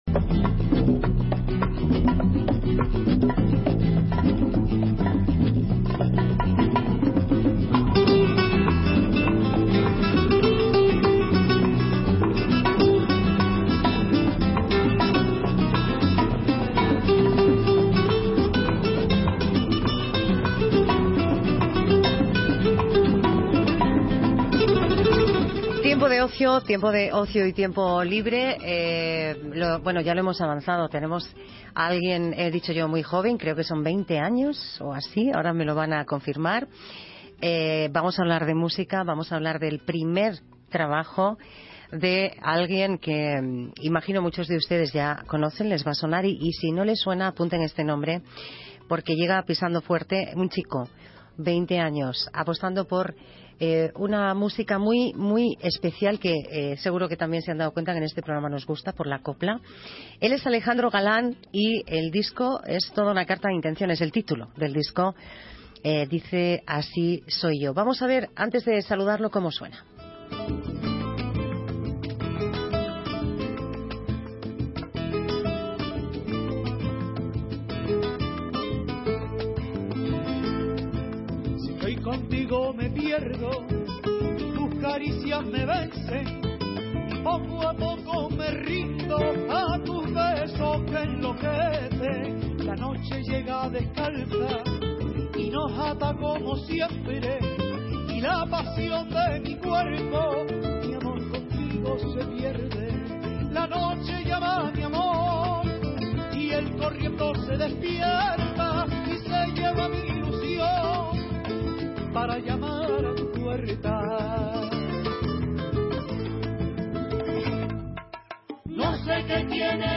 Entrevista emitida en Palabras Mayores, producido por Grupo SENDA en Radio Internacional